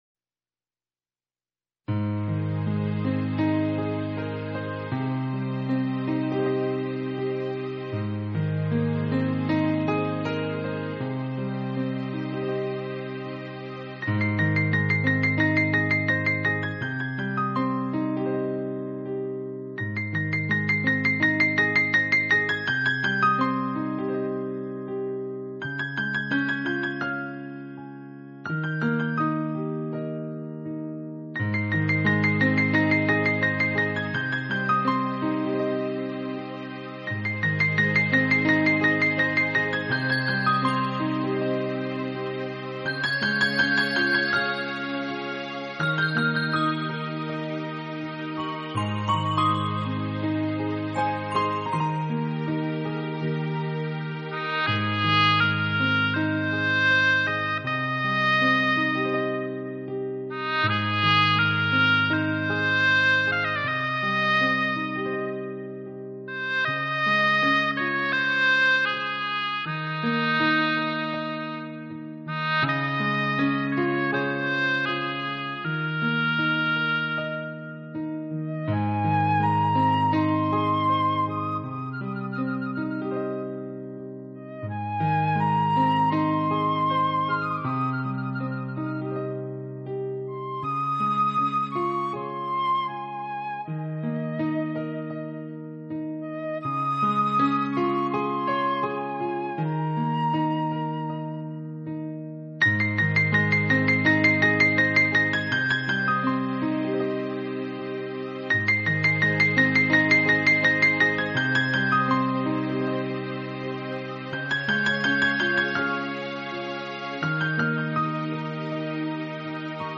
纯音乐
这是一张何等贴心，也何等优美的钢琴与管弦乐的柔情对白。